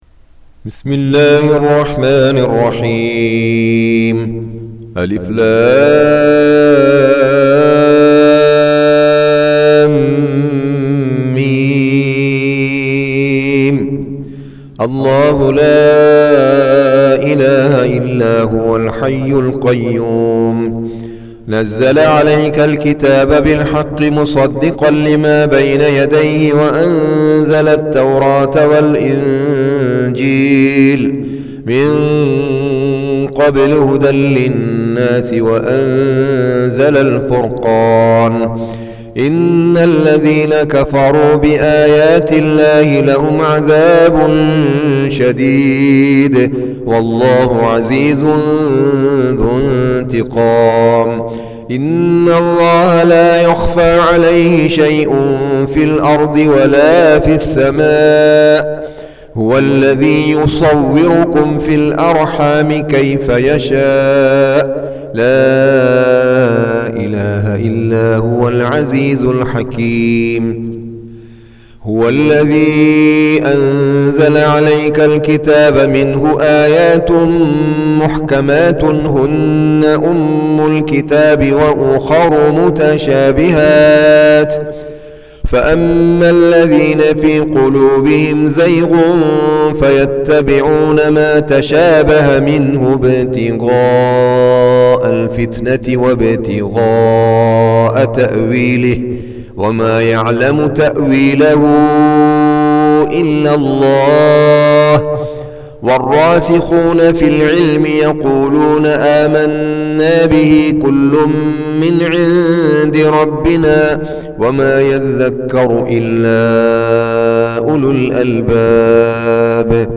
Surah Sequence تتابع السورة Download Surah حمّل السورة Reciting Murattalah Audio for 3. Surah �l-'Imr�n سورة آل عمران N.B *Surah Includes Al-Basmalah Reciters Sequents تتابع التلاوات Reciters Repeats تكرار التلاوات